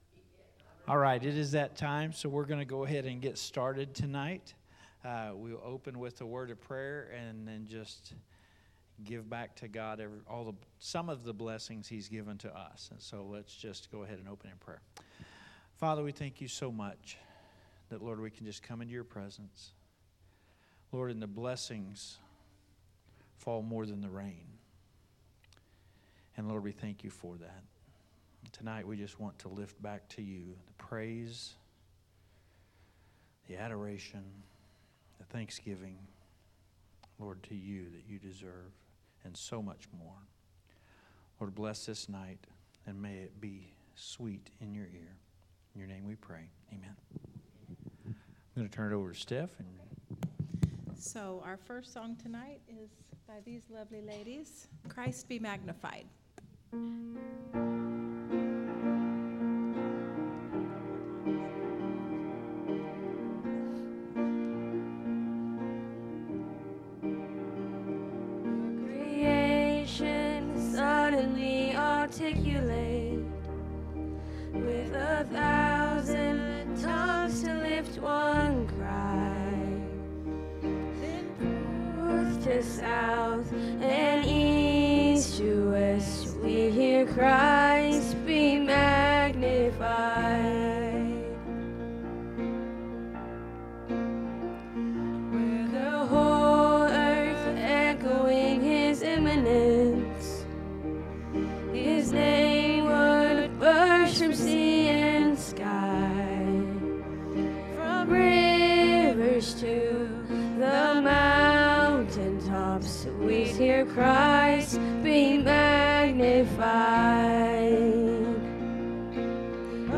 5th Sunday Sing